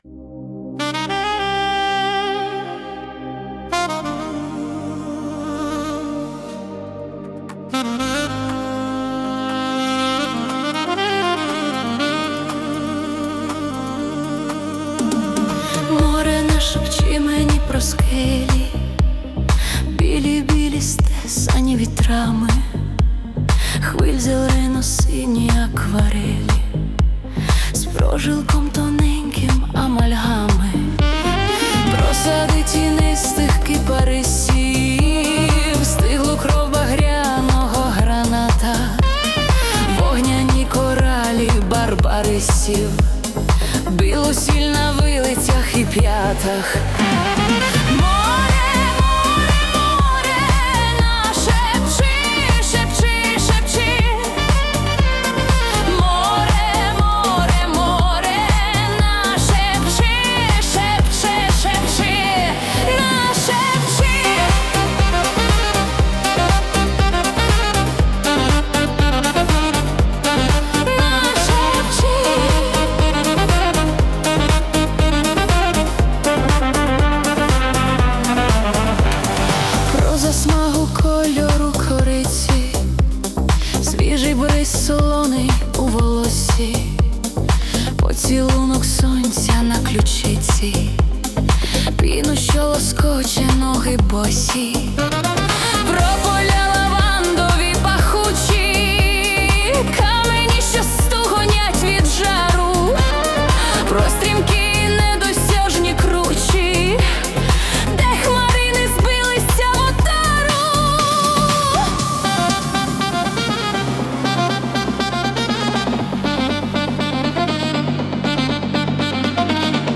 Проникливо-душевна, гарна пісня! 12 16 give_rose 22 22